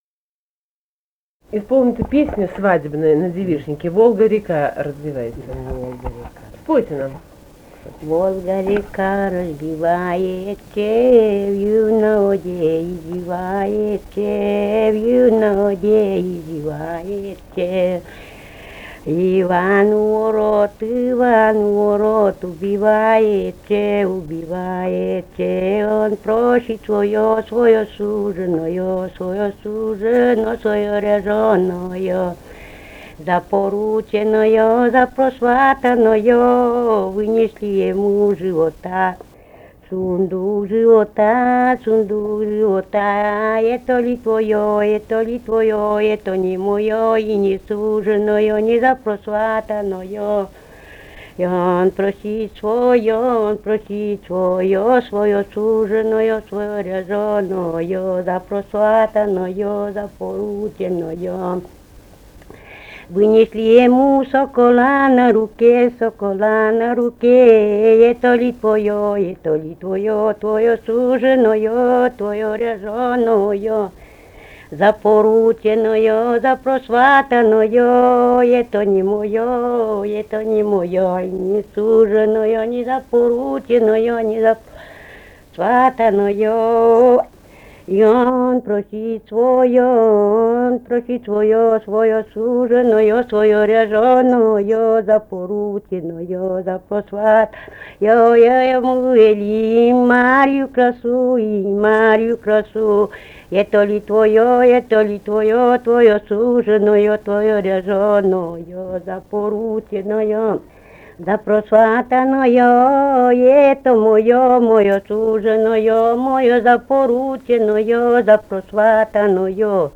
«Волга-речка разливалася» (свадебная на девишнике).